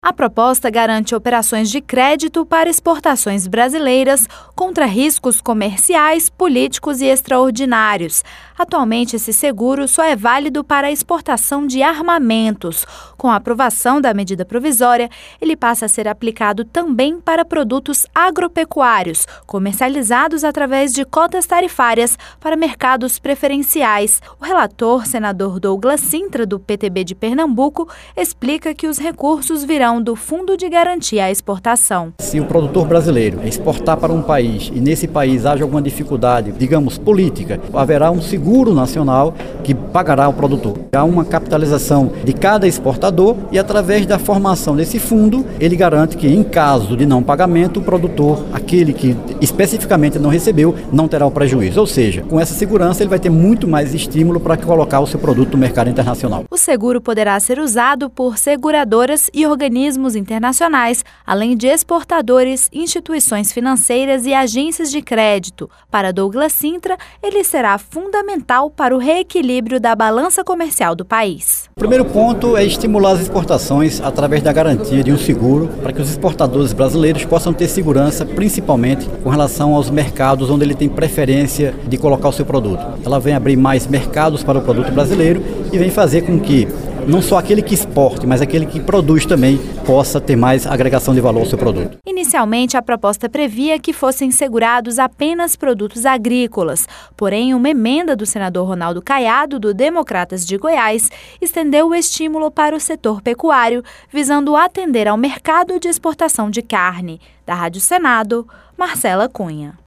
O relator, senador Douglas Cintra, do PTB de Pernambuco, explica que os recursos virão do Fundo de Garantia à Exportação.